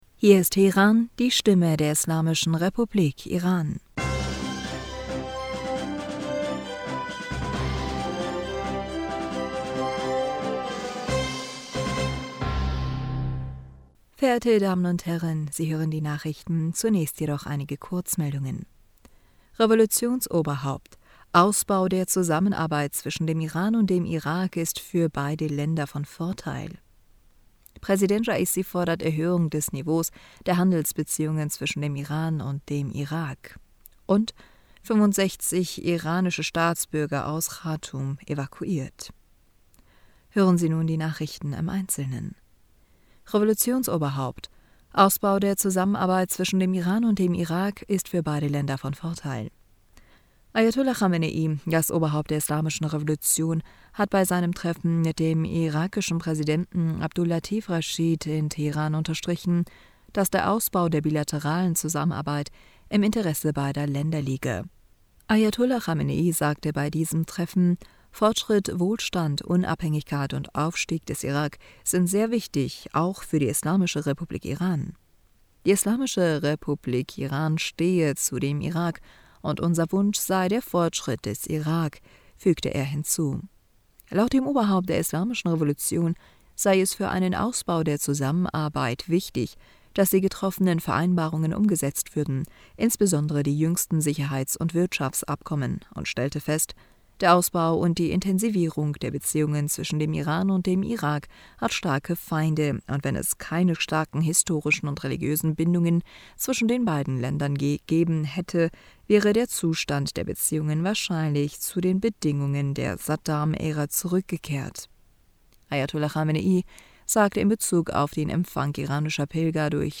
Nachrichten vom 30. April 2023
Die Nachrichten von Sonntag, dem 30. April 2023